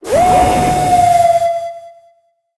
Cri de Zacian dans sa forme Héros Aguerri dans Pokémon HOME.
Cri_0888_Héros_Aguerri_HOME.ogg